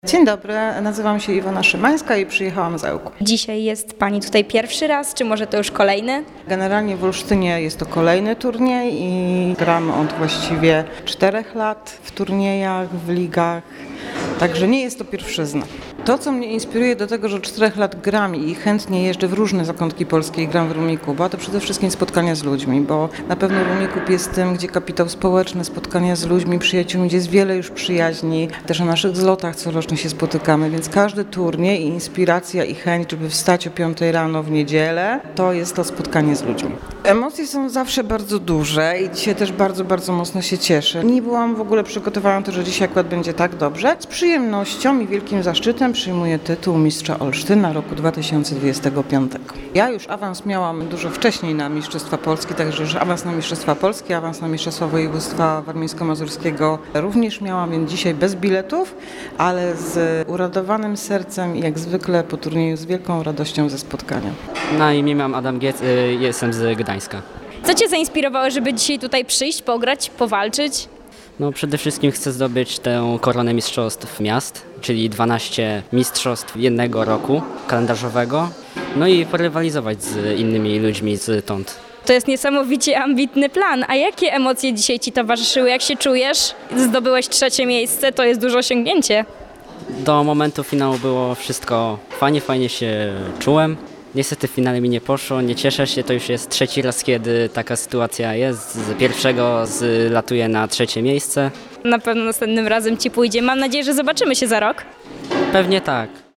Posłuchajcie, co powiedzieli nam laureaci mistrzostw!